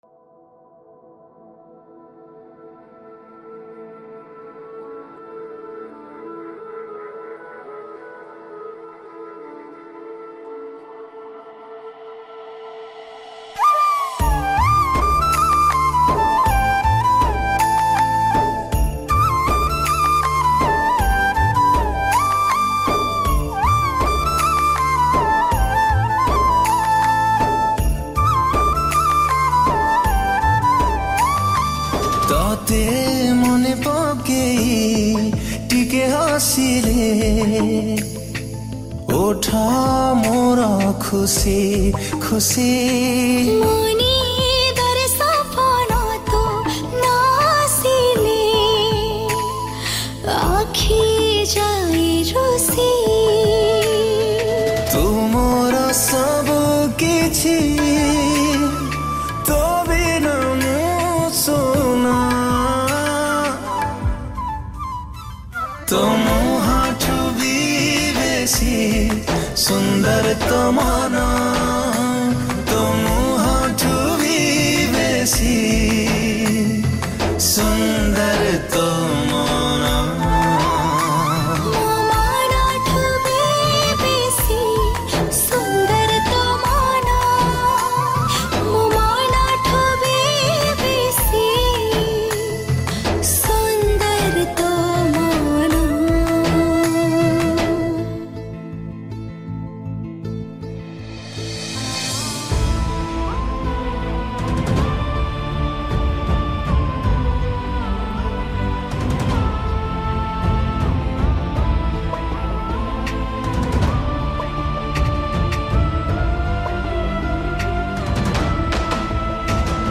Keyboard
Flute